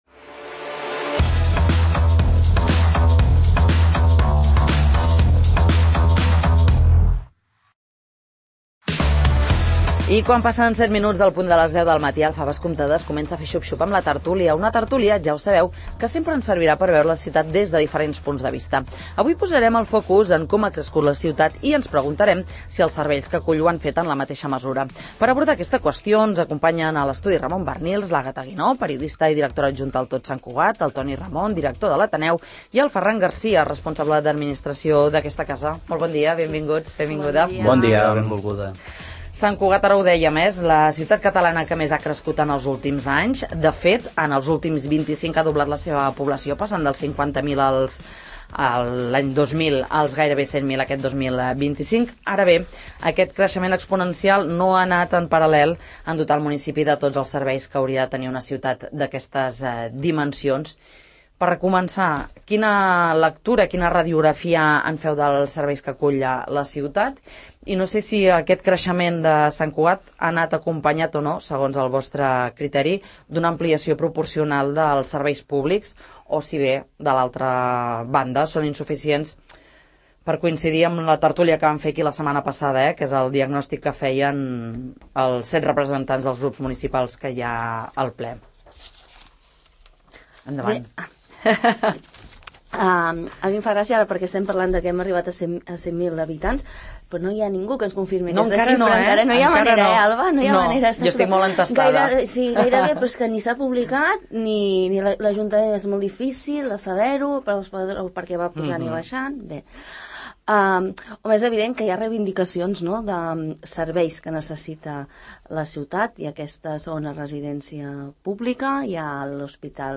Quins serveis manquen a Sant Cugat ara que s'acosta als 100.000 habitants? En parlem a la tert�lia del 'Faves comptades'